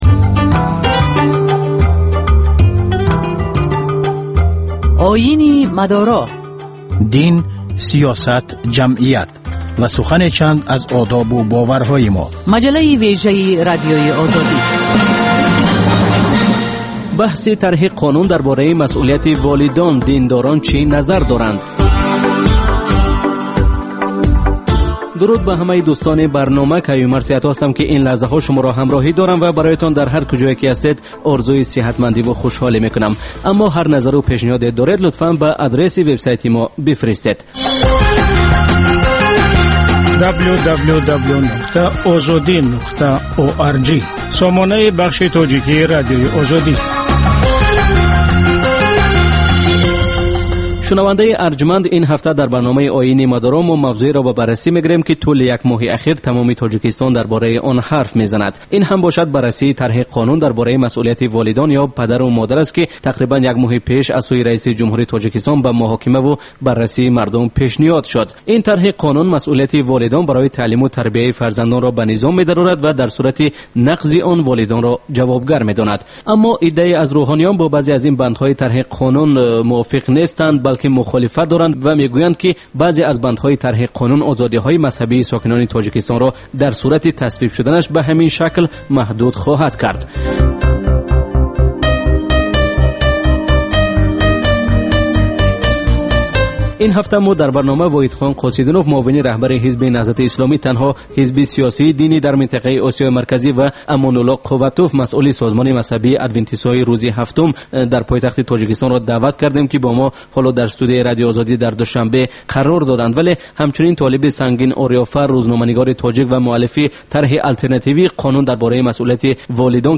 Гузориш, мусоҳиба, сӯҳбатҳои мизи гирд дар бораи муносибати давлат ва дин.